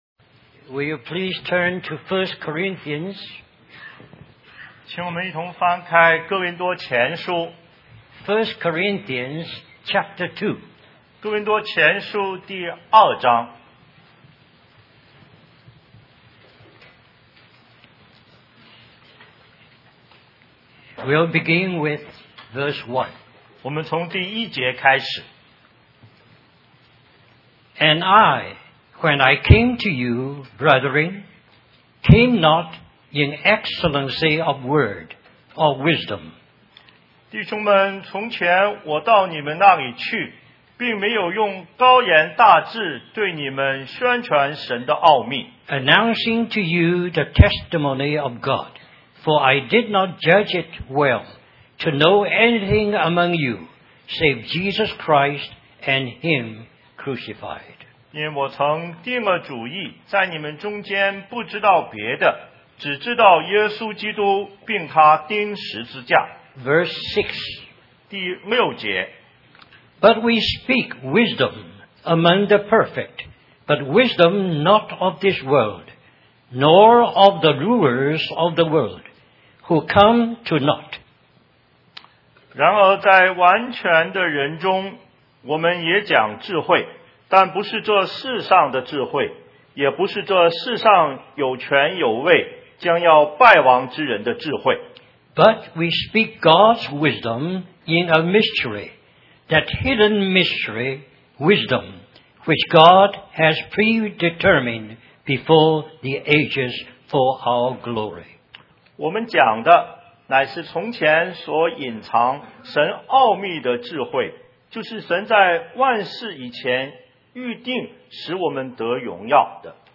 In this sermon, the preacher begins by expressing gratitude for God's love and the sacrifice of sending His Son to the world.